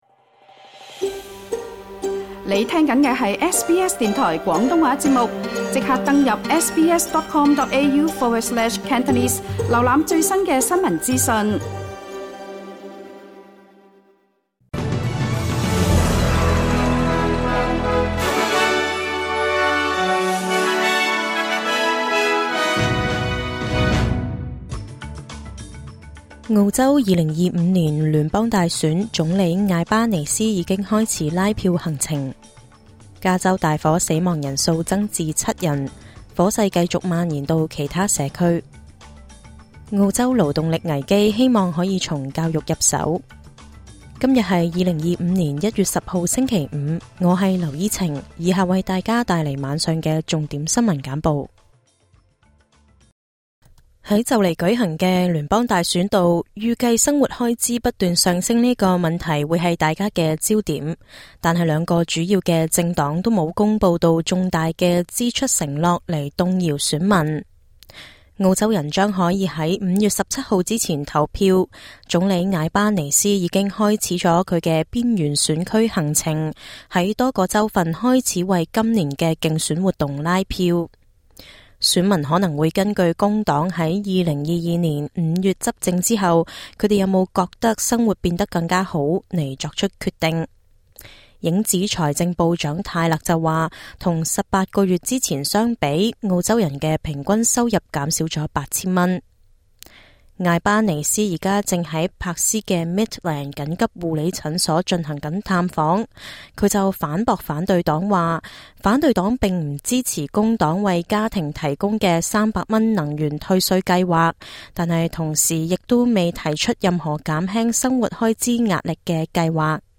請收聽本台為大家準備的每日重點新聞簡報。
SBS 晚間新聞（2025年1月10日） Play 08:32 SBS 廣東話晚間新聞 SBS廣東話節目 View Podcast Series 下載 SBS Audio 應用程式 其他收聽方法 Apple Podcasts  YouTube  Spotify  Download (7.81MB)  請收聽本台為大家準備的每日重點新聞簡報。